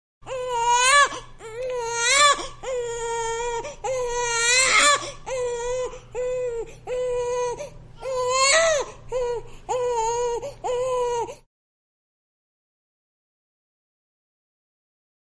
婴儿饿了哭泣声音效免费音频素材下载